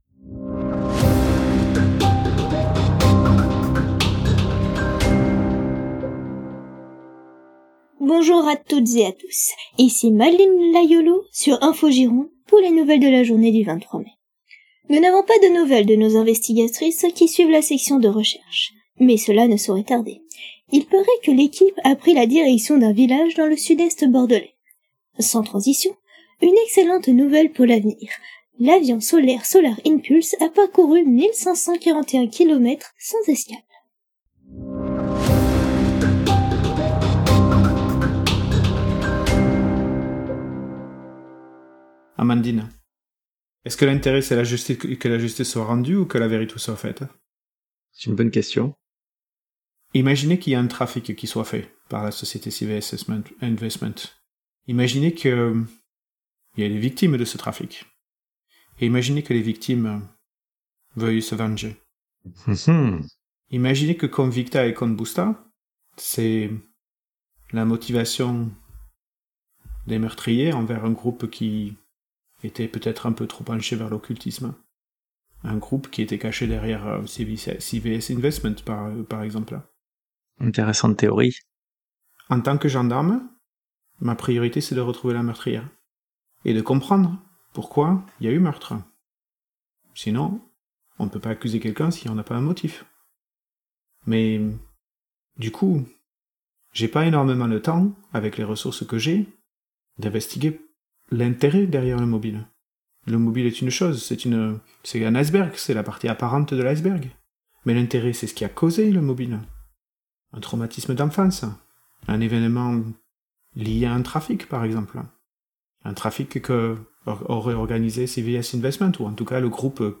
Small Plastic Object Dropped in Large Concrete Basement.aif
9mm Gunshots 1